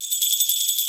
perc_24.wav